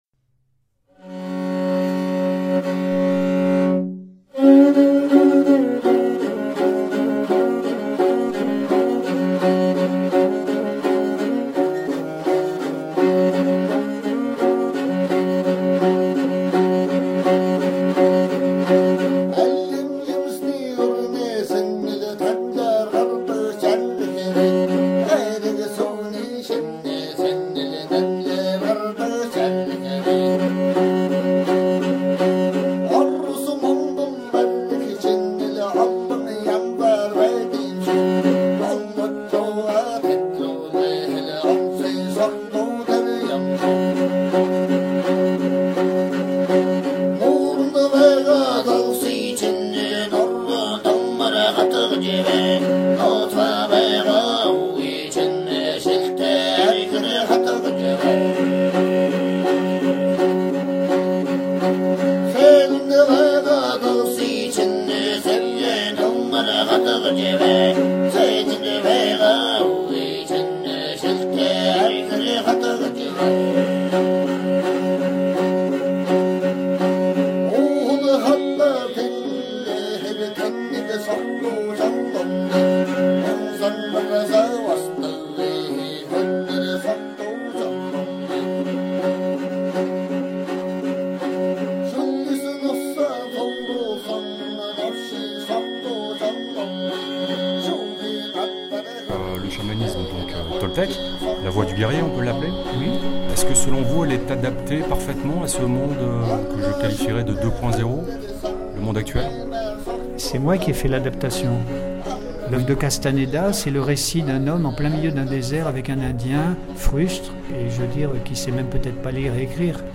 Entretien libre avec
*Les extraits de chants diphoniques sont ceux de chamanes de l'Altaï mongol.